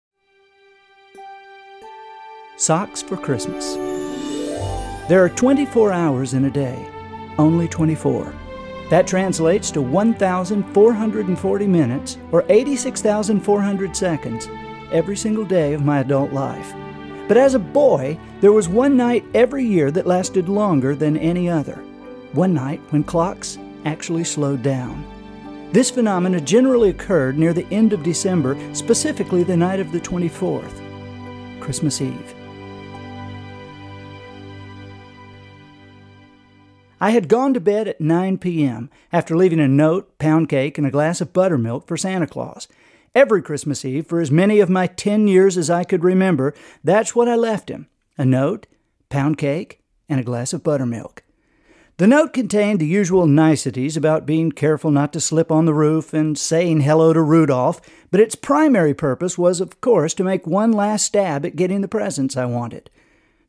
Order Your Copy TODAY | Hear Andy read "Socks" (1:03, 1.17 mb)